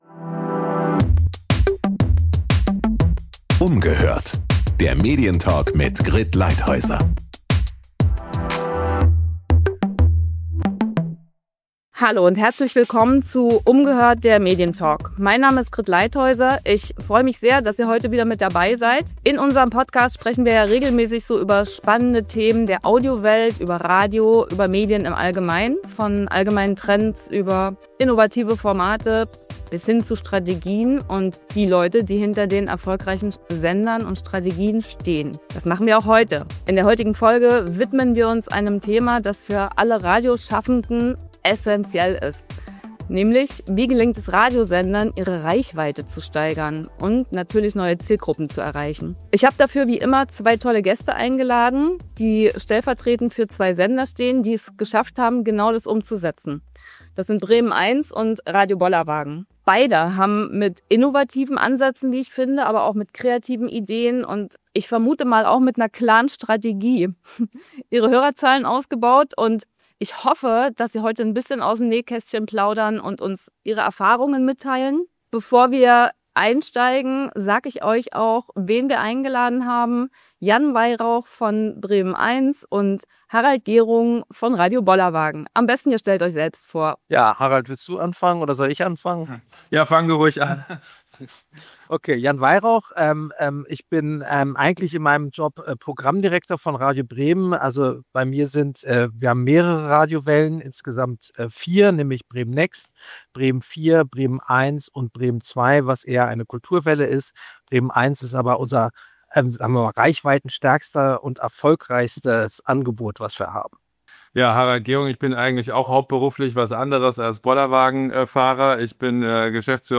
Im Gespräch geht es um Erfolgsstrategien, den Mut zu neuen Formaten, um Zielgruppen anzusprechen, den Einsatz von Social Media sowie um neue Ansätze und Herausforderungen im Radiomarkt. Die Gäste sprechen über ihre Erfahrungen und geben konkrete Tipps, wie Radiosender ihren Erfolg steigern können.